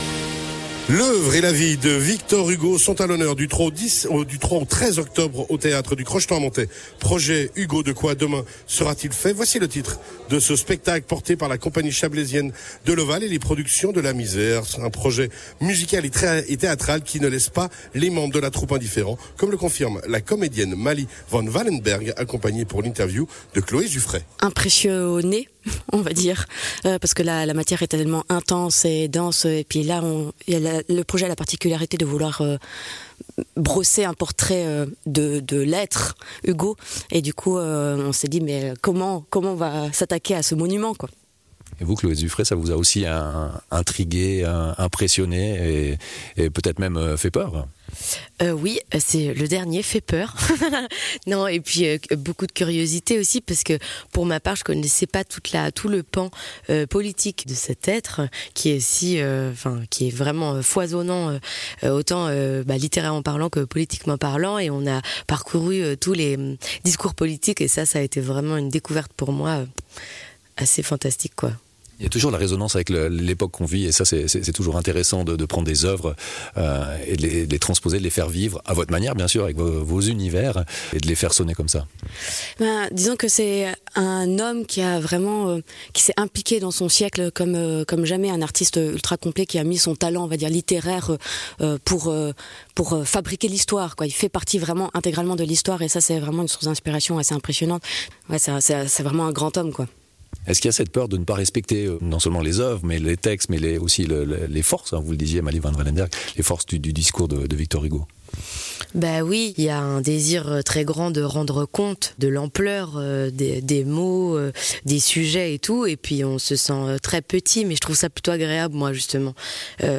comédiennes